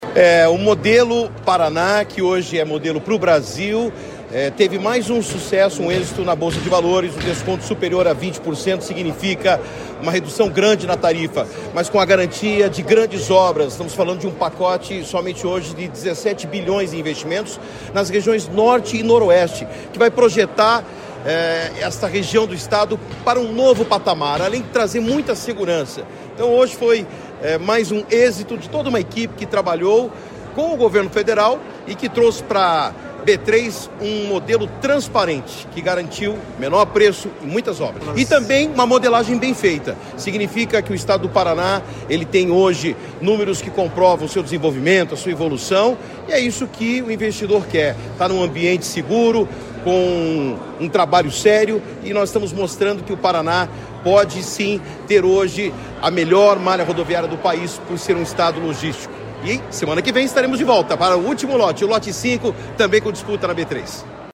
O secretário de Infraestrutura e Logística do Paraná, Sandro Alex, comentou o resultado.